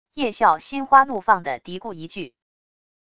叶笑心花怒放的嘀咕一句wav音频